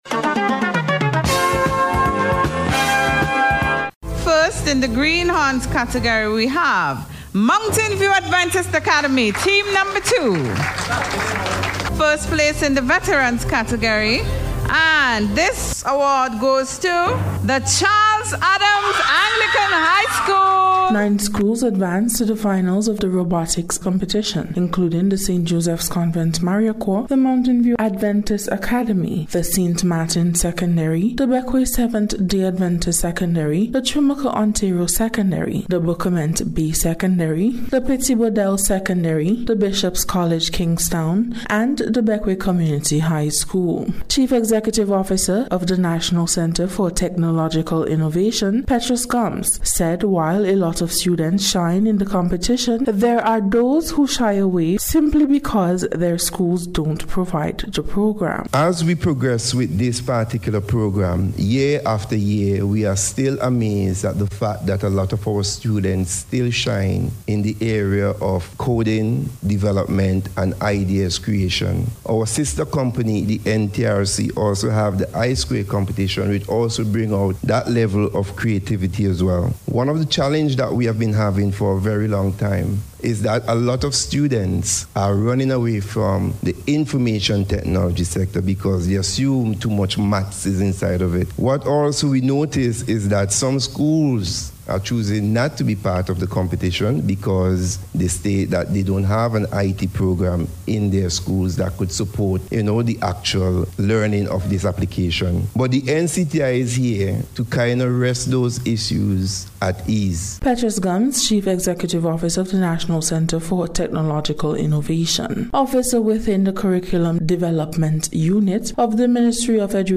NBC’s Special Report- Wednesday 22nd April,2026
ROBOTICS-FINALS-SPECIAL-REPORT.mp3